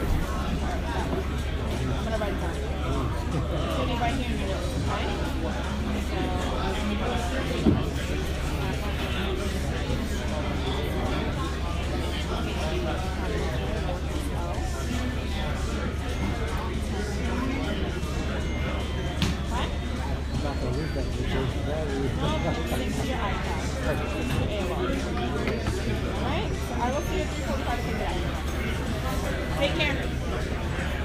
Field Recording
Sounds Heard: loud conversations, weird background music, people complaining about broken computers. money being counted.